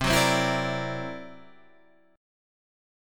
C 9th Flat 5th